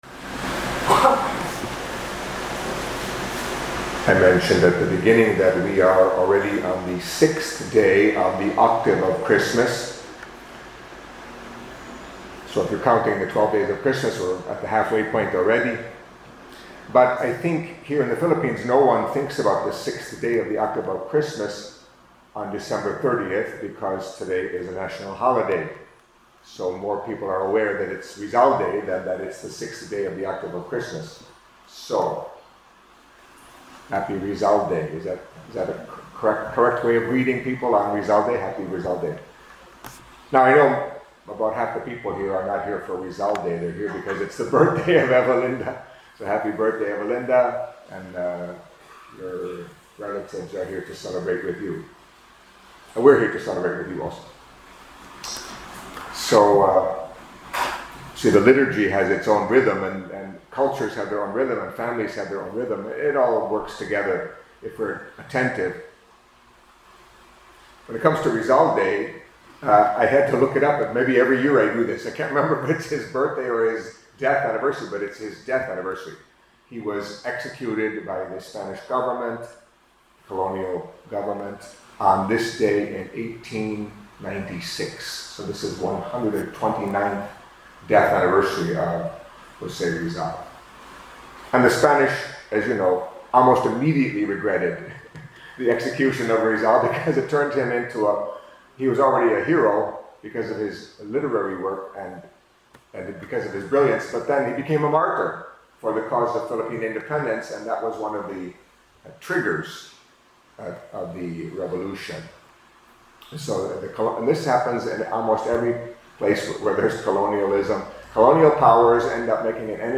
Catholic Mass homily for Tuesday, Sixth Day within the Octave of Christmas